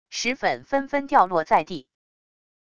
石粉纷纷掉落在地wav音频